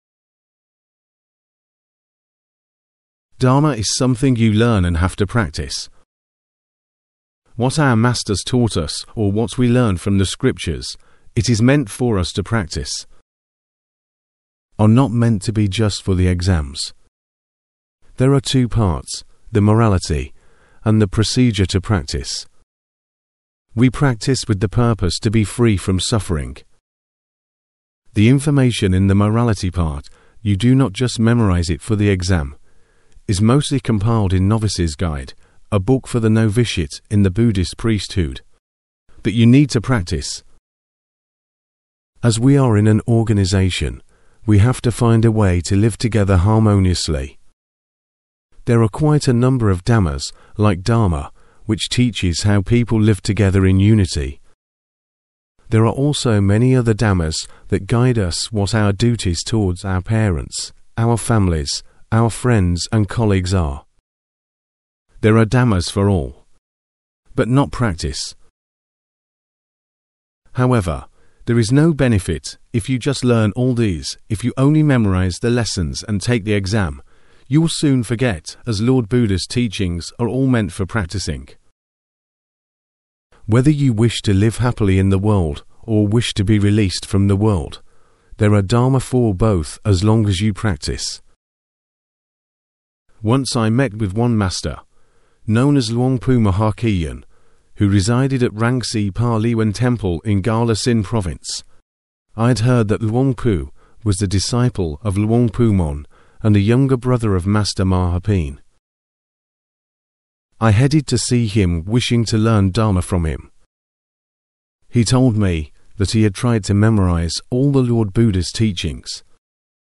Edited and Narrated - Do Not Neglect Your Own Mind - 23 July 2023